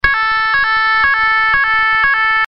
Serie: SIRENAS ELECTRÓNICAS
Elevada potencia acústica - 115dB